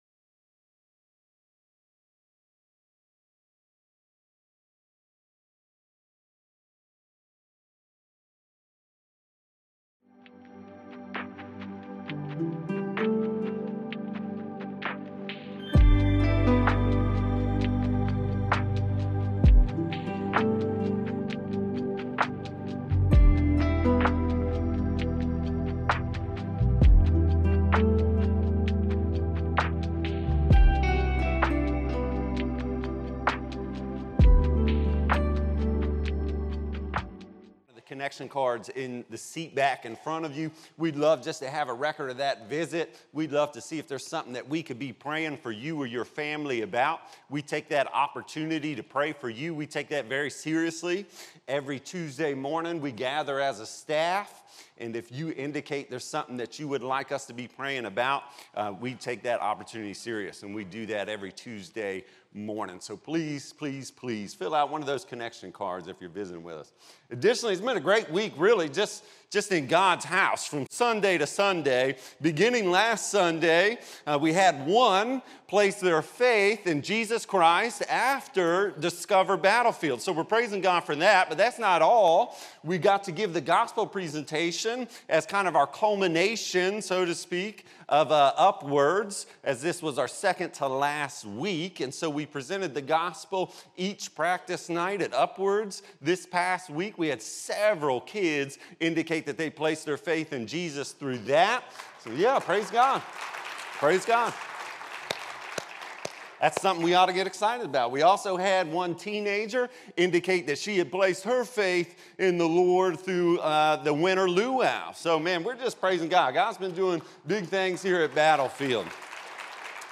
Sermons | Battlefield Baptist Church